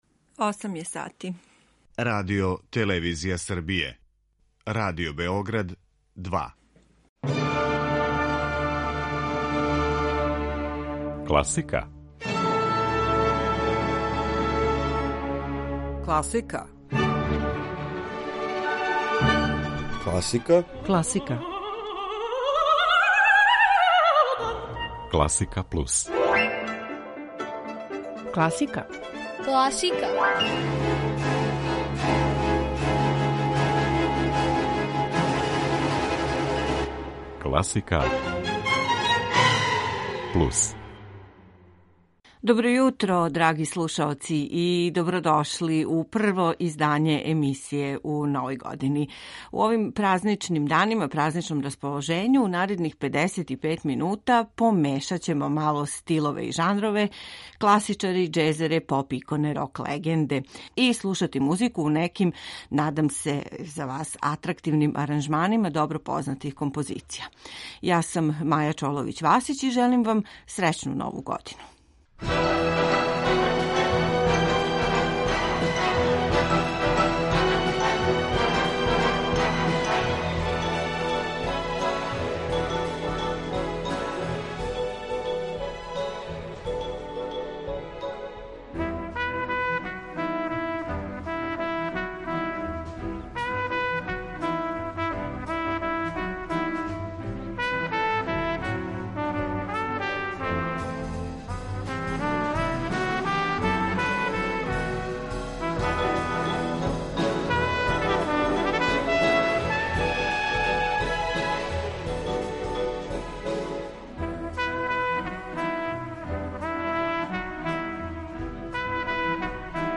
слушамо композције из домена класике, џеза и world музике